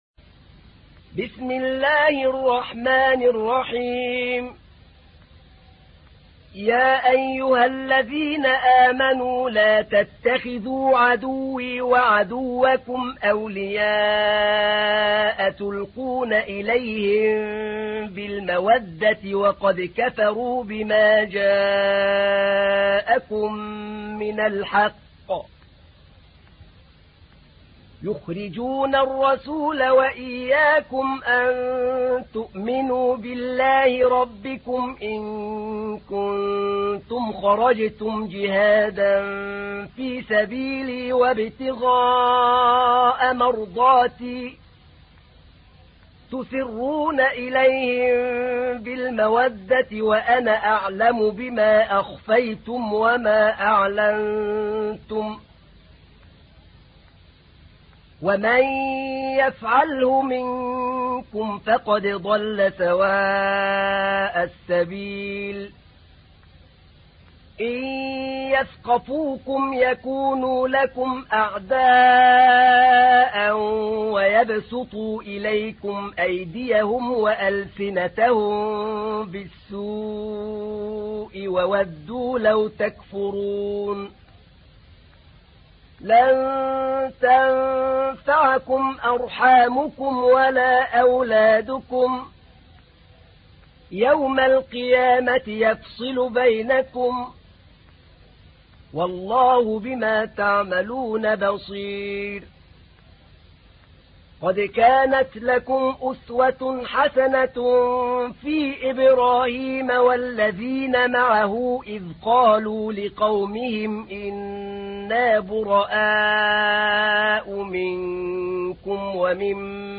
تحميل : 60. سورة الممتحنة / القارئ أحمد نعينع / القرآن الكريم / موقع يا حسين